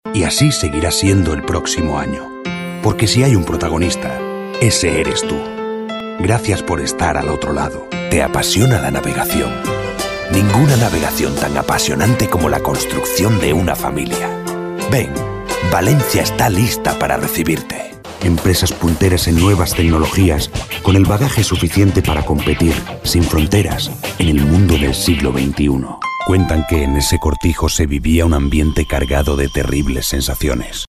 İspanyolca Seslendirme